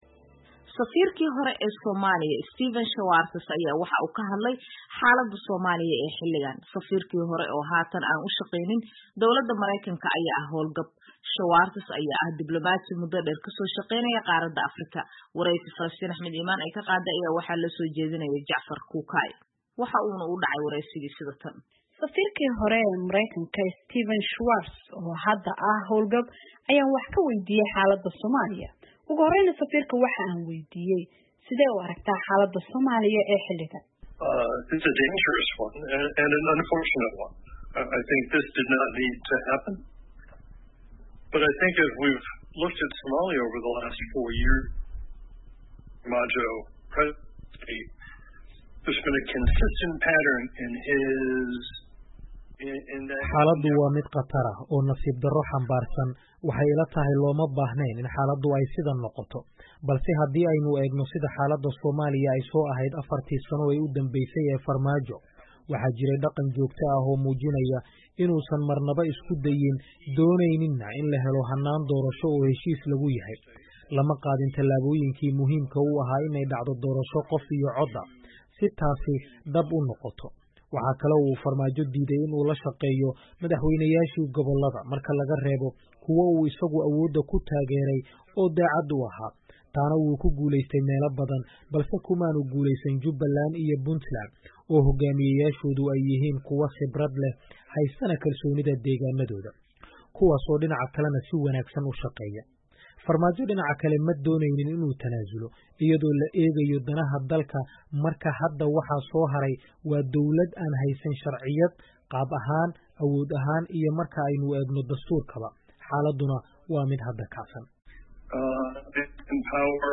Wareysi: Safiirkii hore ee Mareykanka oo ka hadlay xaaladda Soomaaliya